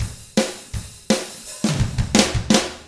164ROCK F3-R.wav